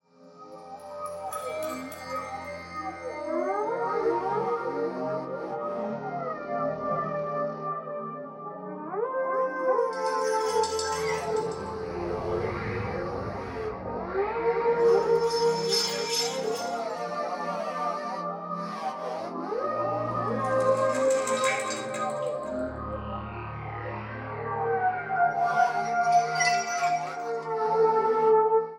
サンプル音源は全てステレオ(2ch)です。